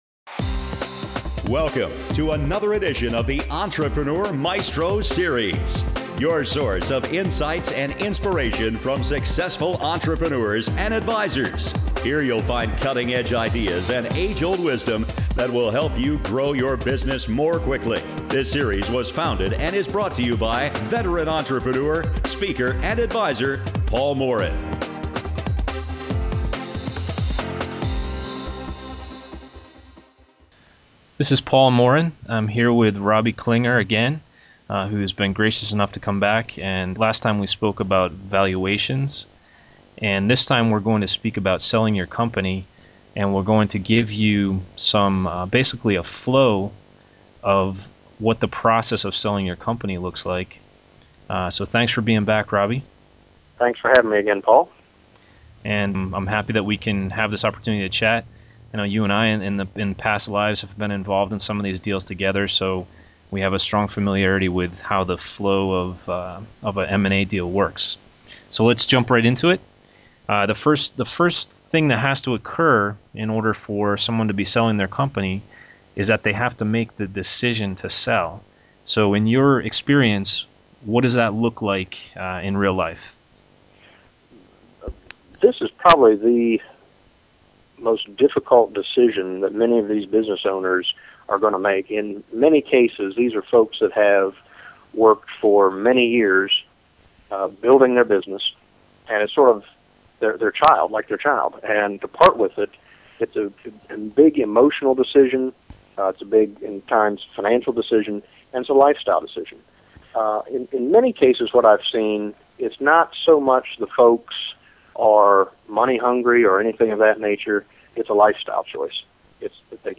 Selling Your Company - Step-by-Step - Audio Interview - Company Founder